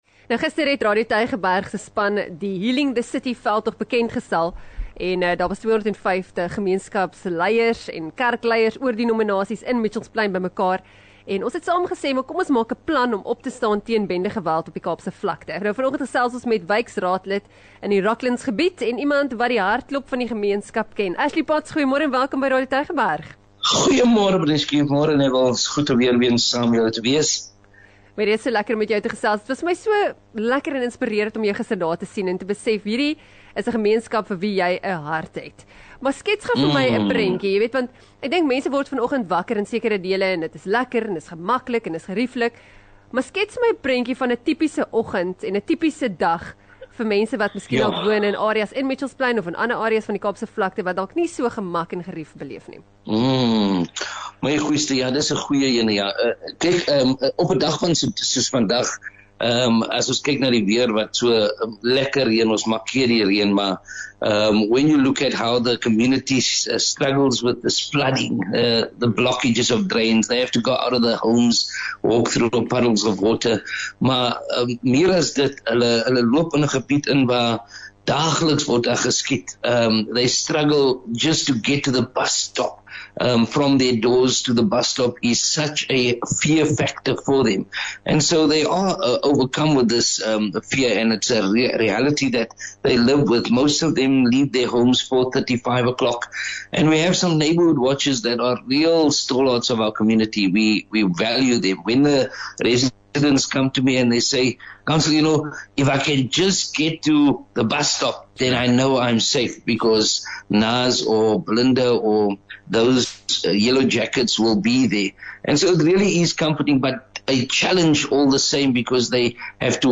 26 Aug Die Real Brekfis gesels met Ashley Potts, wyksraadslid vir Rocklands in Mitchells Plain